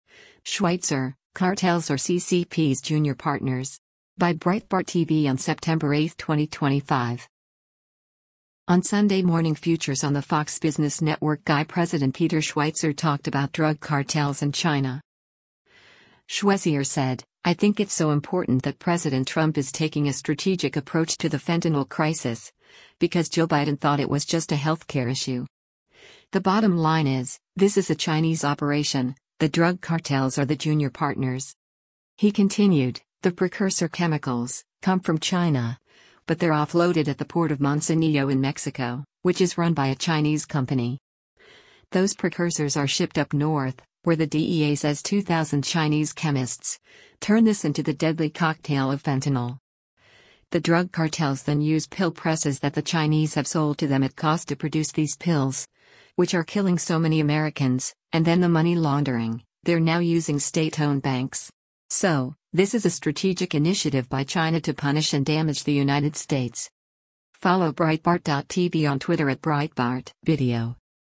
On “Sunday Morning Futures” on the Fox Business Network GAI President Peter Schweizer talked about drug cartels and China.